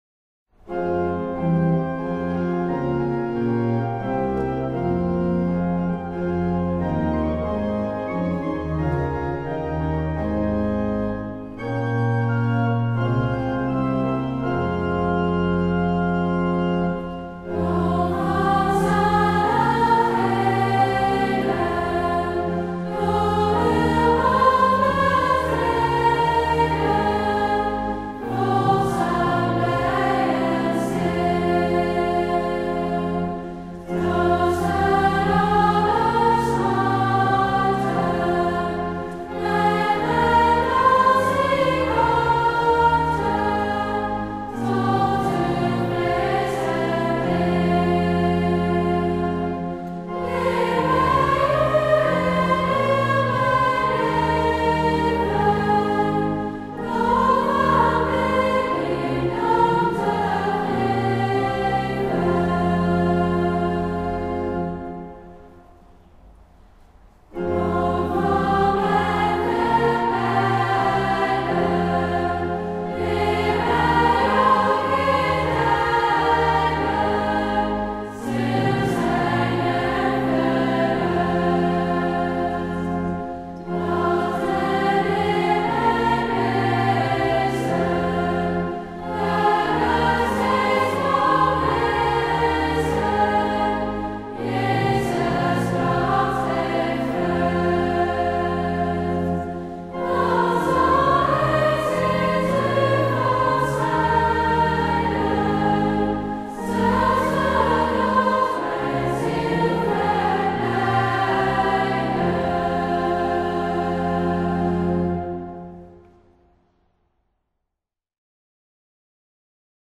Ter herinnerring aan het 60 jarig jubileumconcert van leerlingen van het Driestarcollege in de grote kerk te Gouda
piano